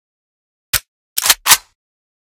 unjam.ogg